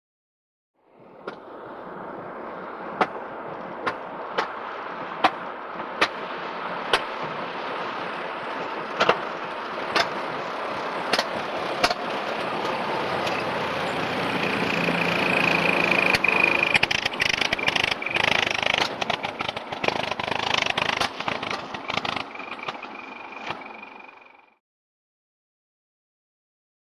Automobile; By; Vintage Dennis Truck Up And Past At 20 Mph With Engine Miss Fires And Almost Stalling At Mic. Then Struggles Away To Long Shot.